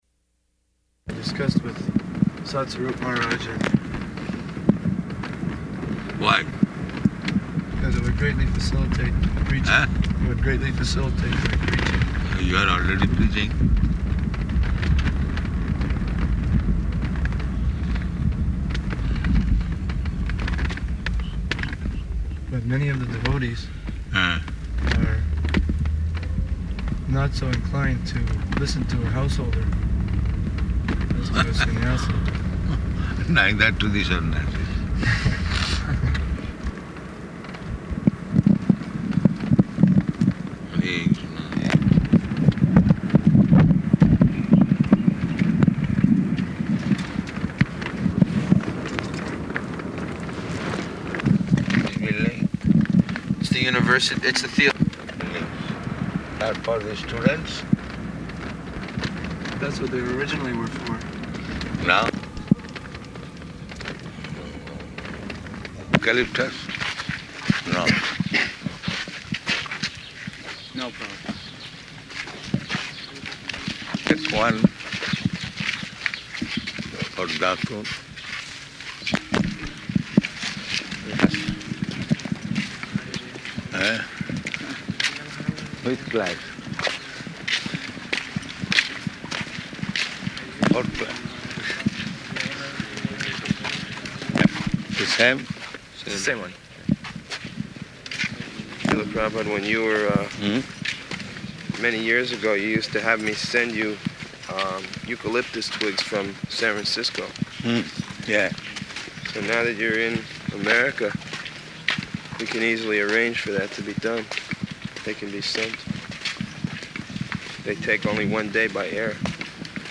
Type: Walk
Location: Chicago